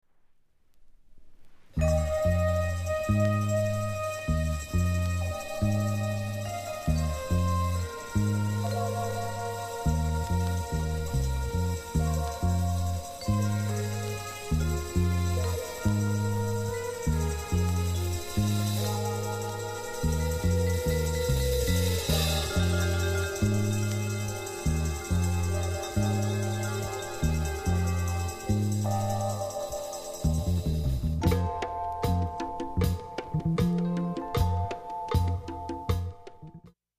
◆盤質Ａ面/EX+ 下記注意◆盤質Ｂ面/EX+ A-1はじめわずかなプツ出ます。ほとんどの人が聞こえないレベルです。